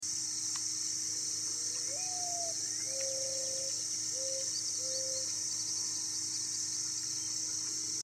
Mourning Dove Makes Its Lovely Sound Effects Free Download